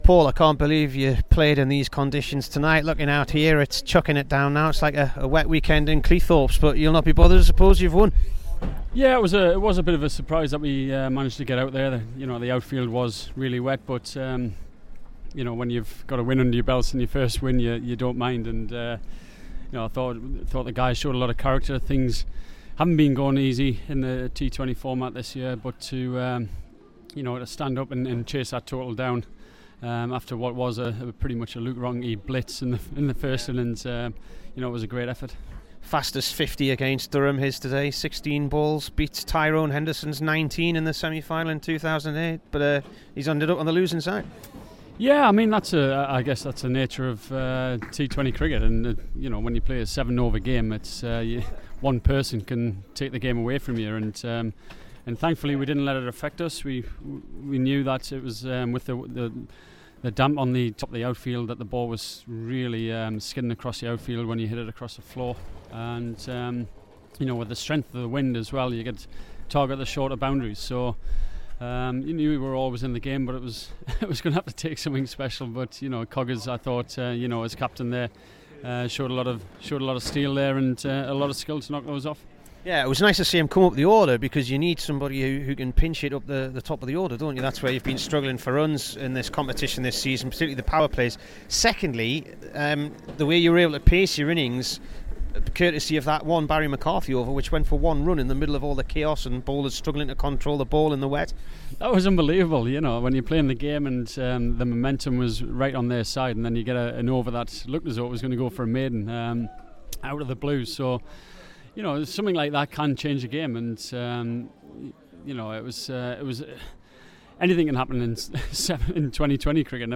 PAUL COLLINGWOOD INT